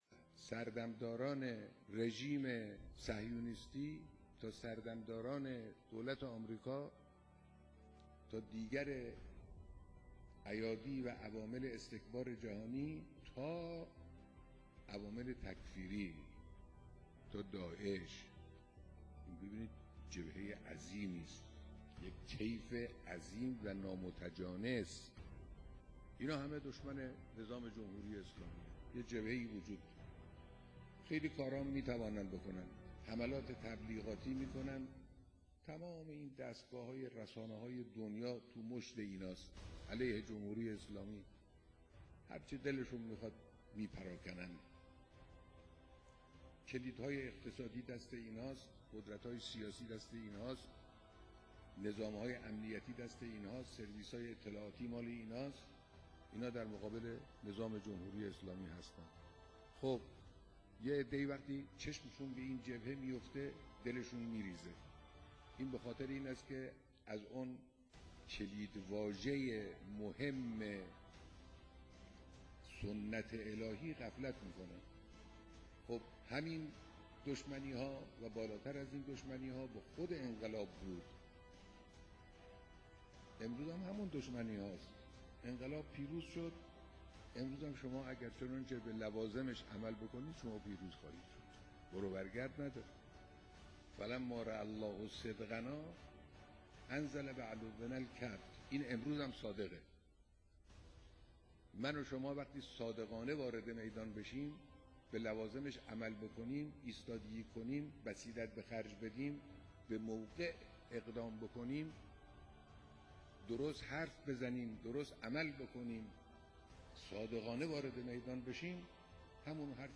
نماهنگی زیبا از بیانات مقام معظم رهبری-رمز ماندگاری انقلاب اسلامی 3